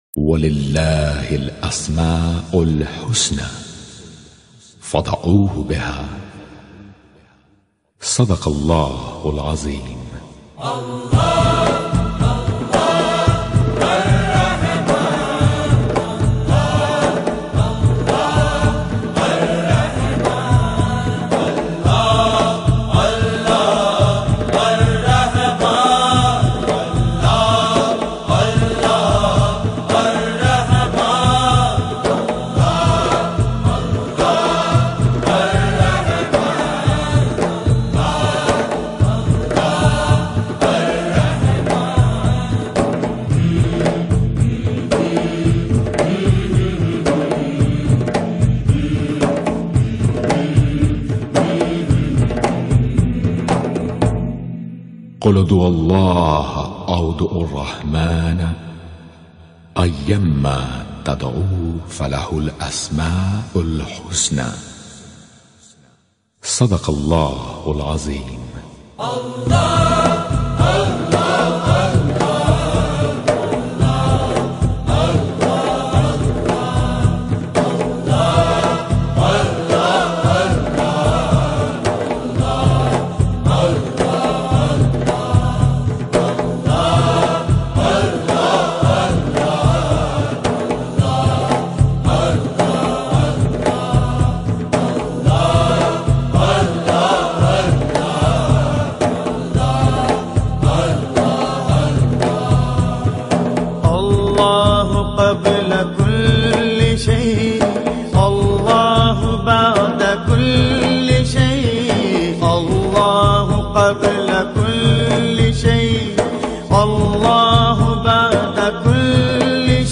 This audio track offers a serene and educational experience, perfect for deepening your understanding and connection with the Divine Names.